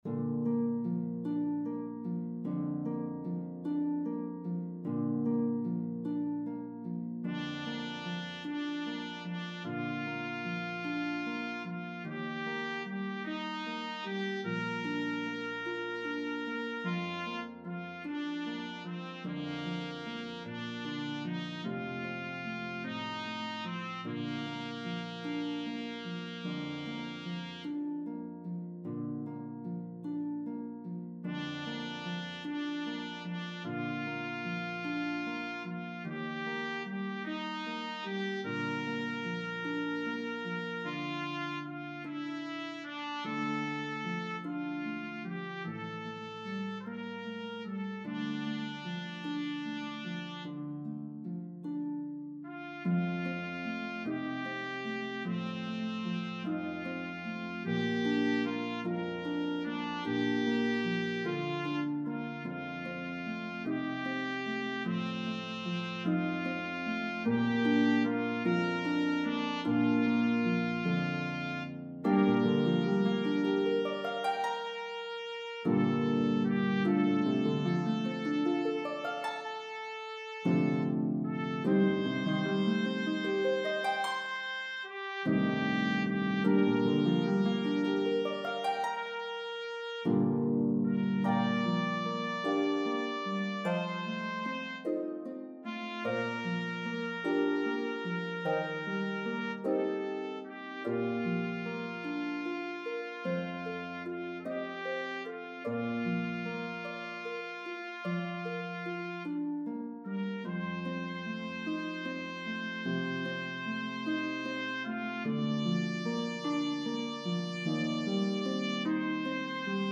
The melody of the two verses varies in rhythm & pitches.